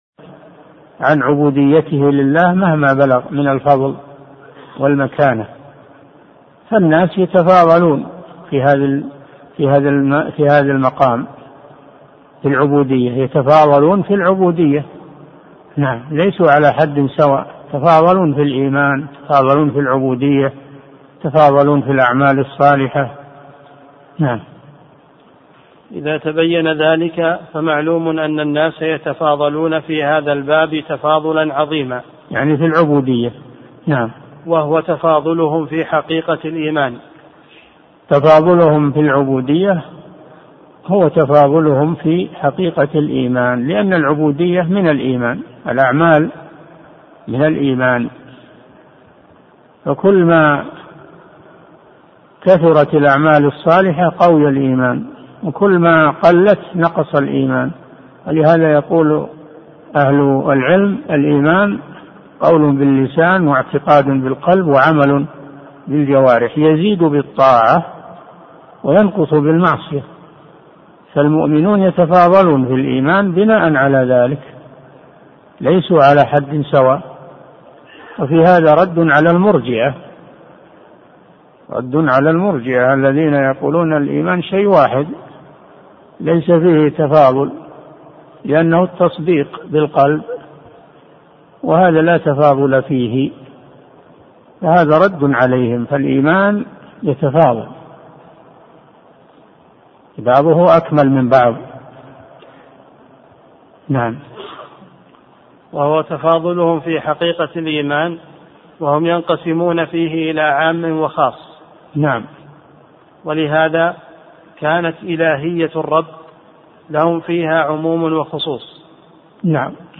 أرشيف الإسلام - أرشيف صوتي لدروس وخطب ومحاضرات الشيخ صالح بن فوزان الفوزان